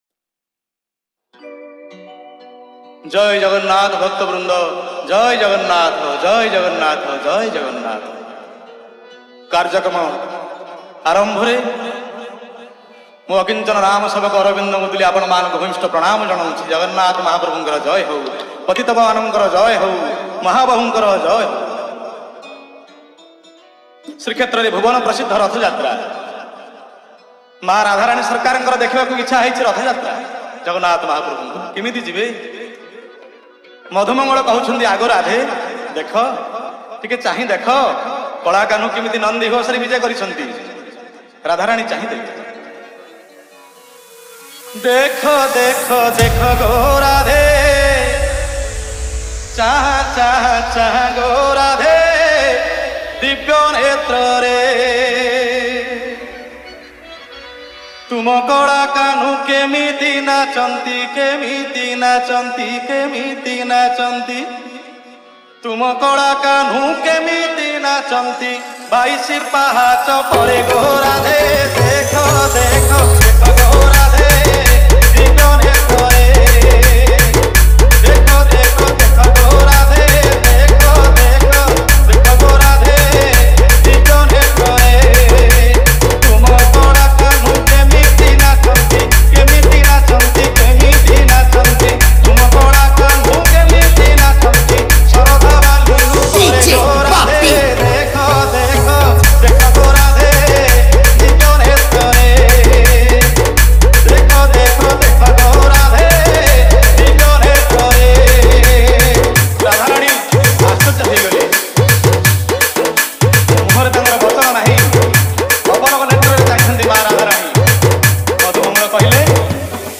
Bhajan Dj Song Collection 2025